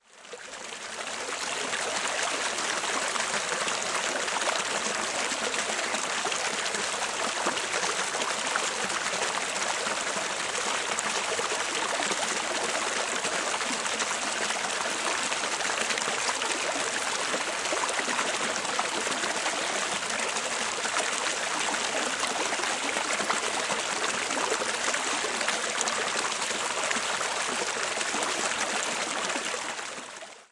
水 " 水流过遥远的激流 3
描述：流经在一条小河的一些急流的水的遥远的野外记录。 使用Zoom H6 Midside模块在昆士兰州Springbrook国家公园录制。
标签： 小溪 汩汩 滴流 流淌 远处 小溪 流量 液体 现场记录 河流
声道立体声